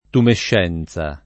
tumescenza [ tumešš $ n Z a ] s. f.